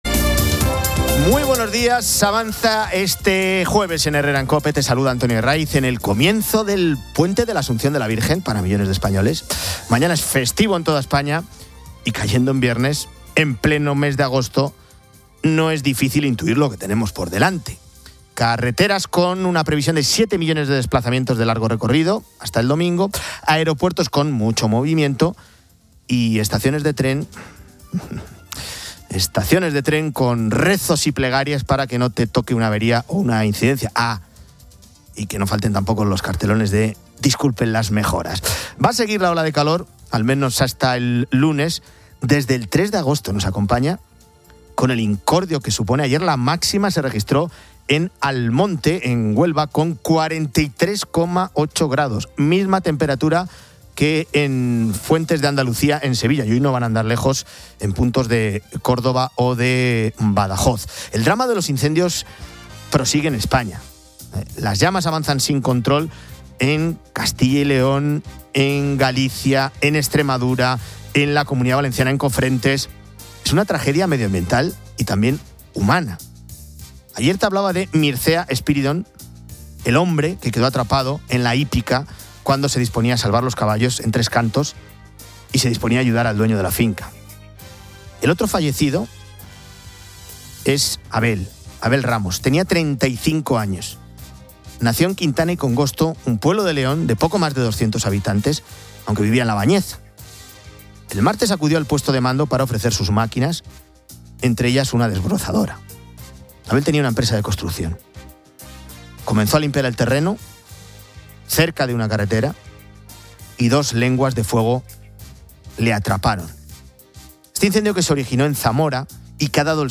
Herrera en COPE 07:00H | 14 AGO 2025 | Herrera en COPE El audio es un resumen de noticias de la mañana, destacando la festividad de la Asunción y el inicio de un puente vacacional con mucho tráfico. La ola de calor persiste en España.